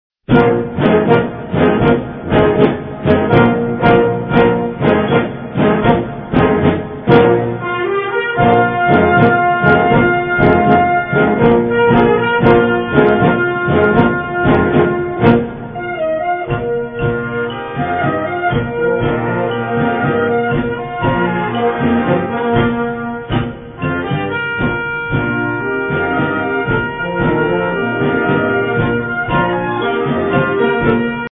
Schwierigkeit: A
Besetzung: Blasorchester